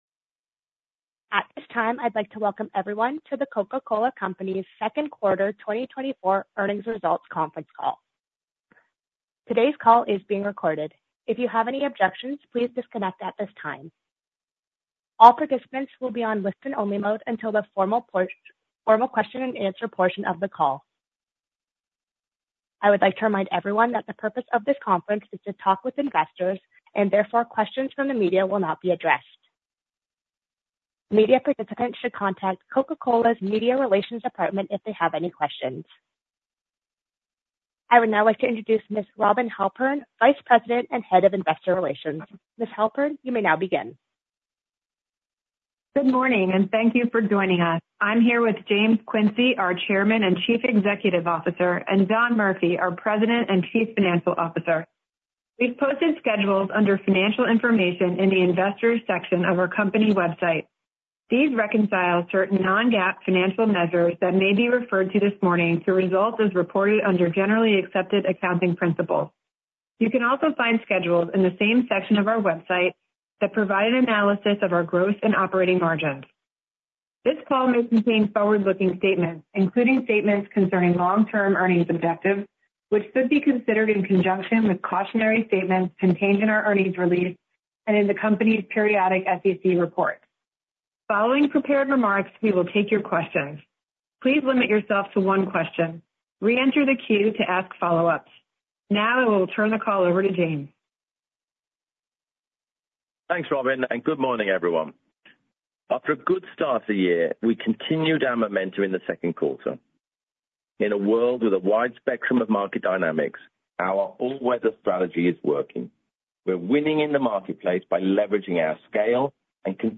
Earnings Call Q2 2024 Audio